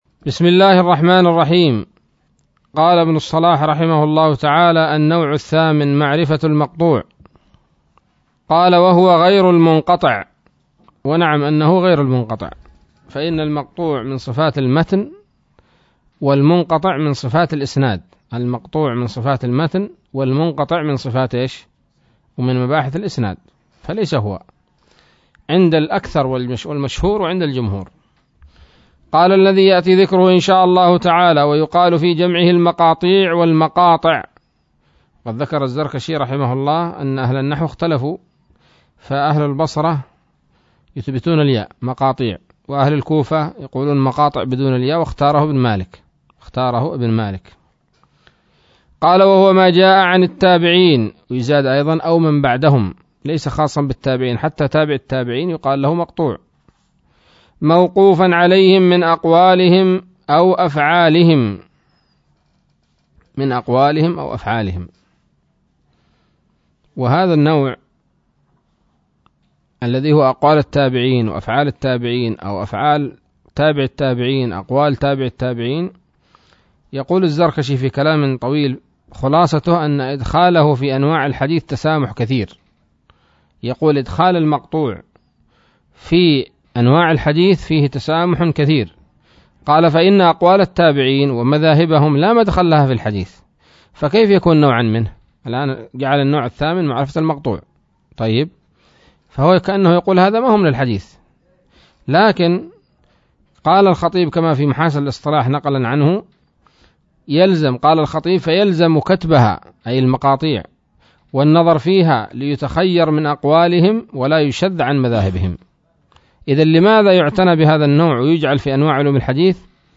الدرس الحادي والعشرون من مقدمة ابن الصلاح رحمه الله تعالى